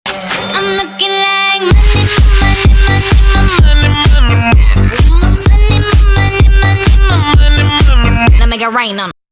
youtube-twitch-alert-sound-effect-donation-1_grS0oaF.mp3